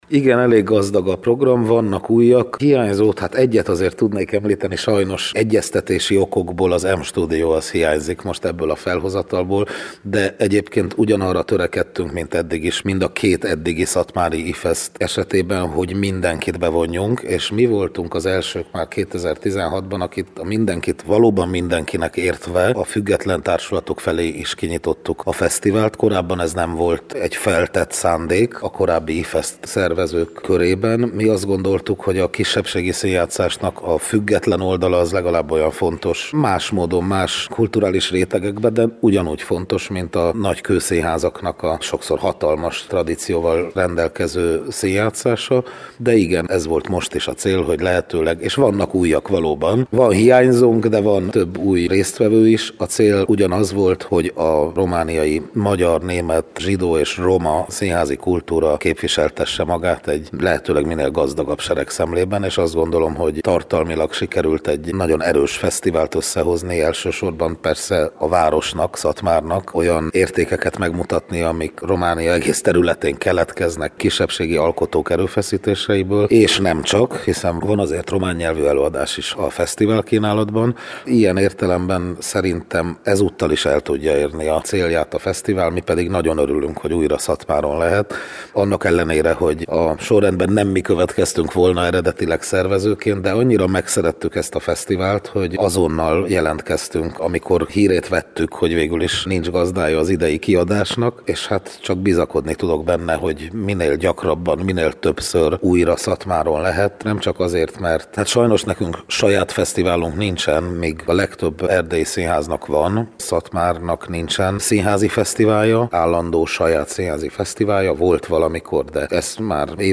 Címlap » Műsorok » Hangoló » Kezdődik az IFESZT Szatmáron